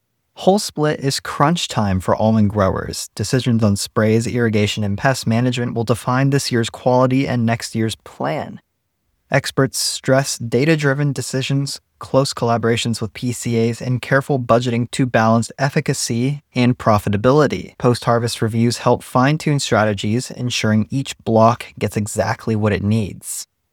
Listen to the audio version of this article. (Generated by A.I.)